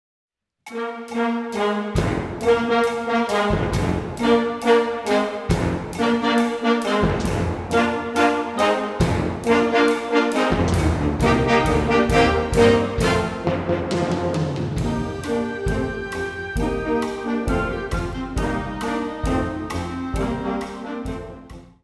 Besetzungsart/Infos 4Part; Perc (Schlaginstrument)